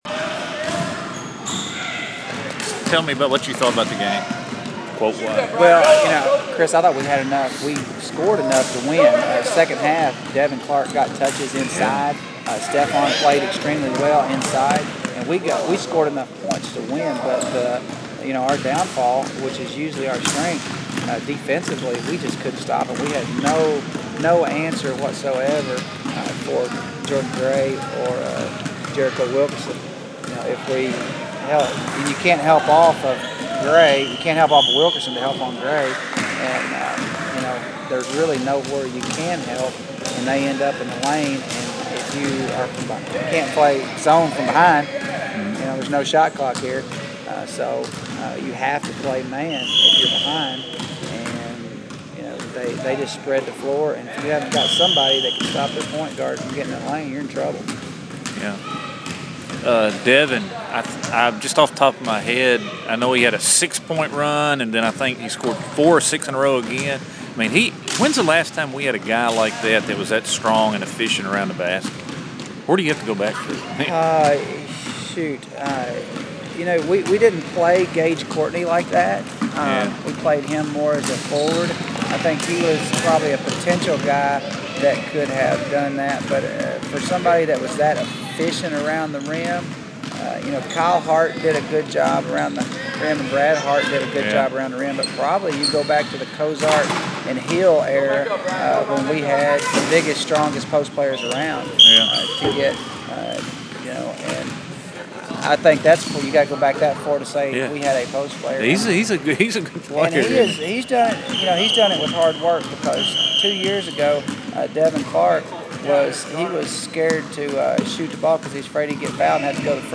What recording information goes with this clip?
Conducted after the boys' game against Lyon